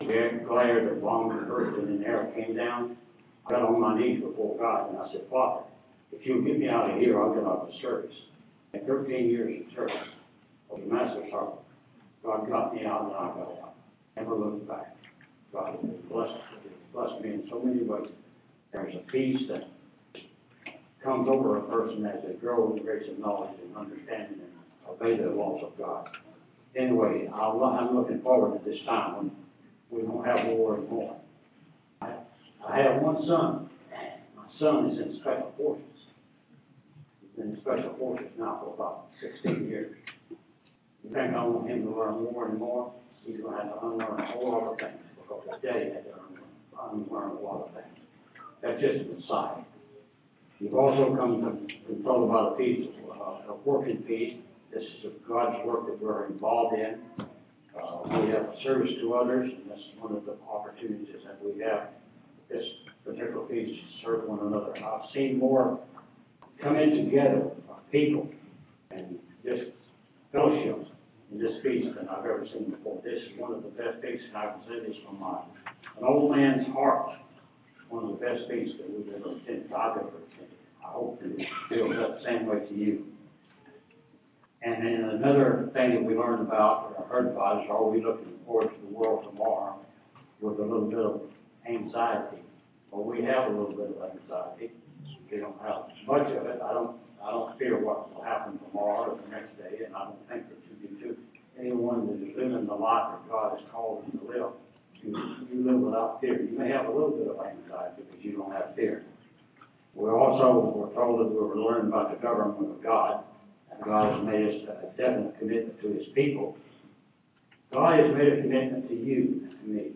This sermon was given at the Galveston, Texas 2016 Feast site.